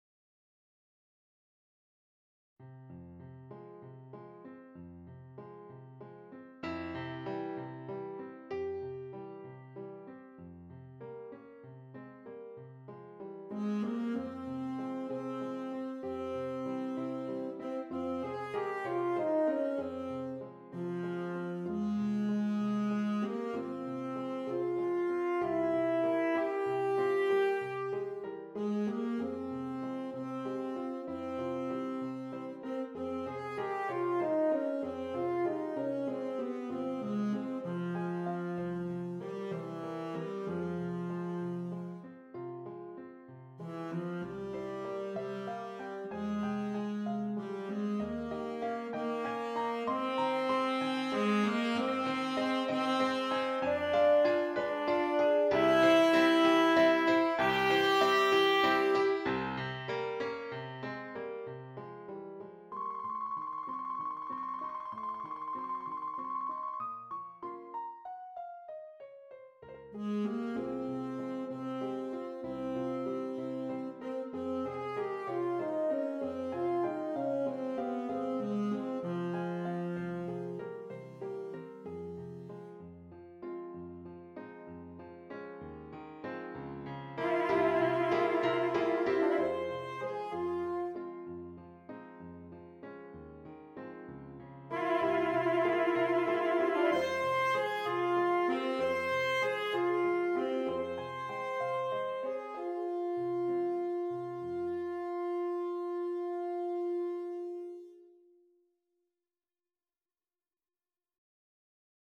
Tenor Saxophone and Keyboard